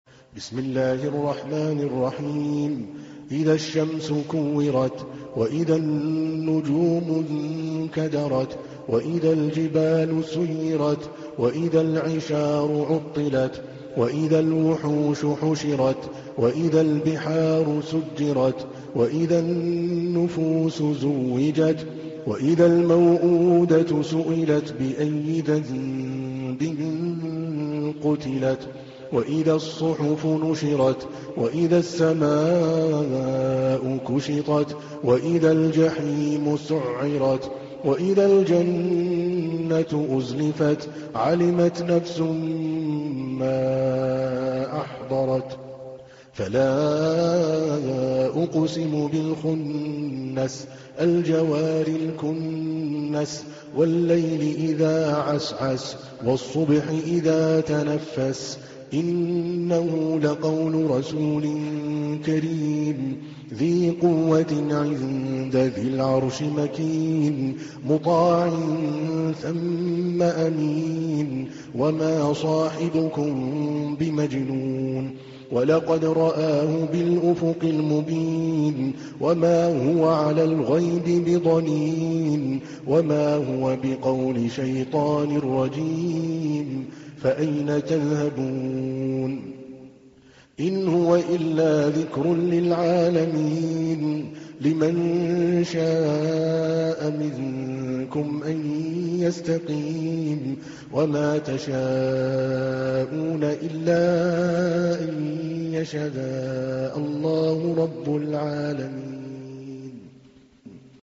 تحميل : 81. سورة التكوير / القارئ عادل الكلباني / القرآن الكريم / موقع يا حسين